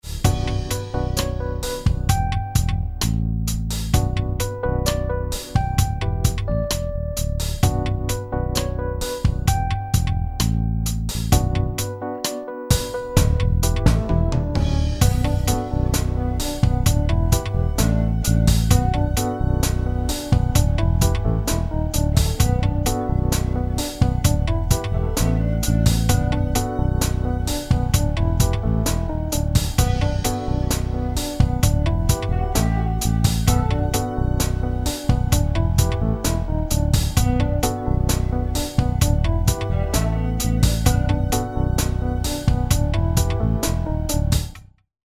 in_a_situation_organ_clip.mp3